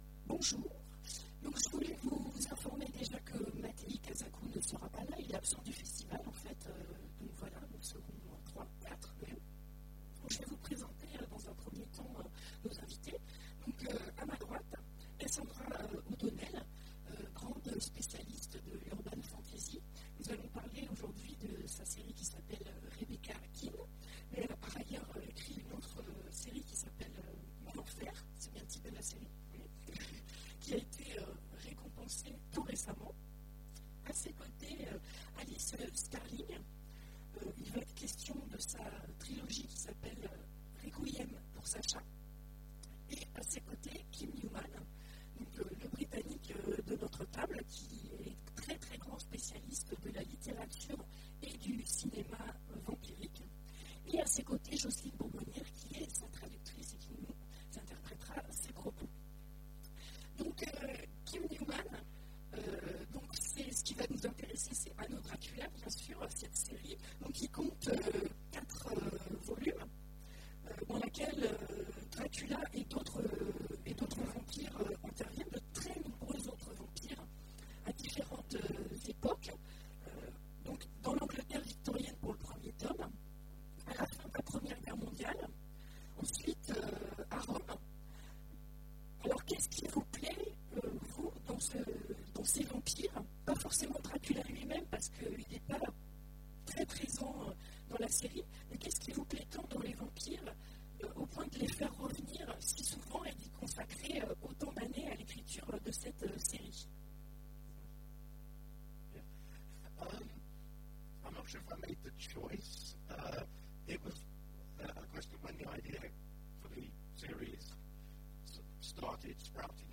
Imaginales 2015 : Conférence Au bonheur des vampires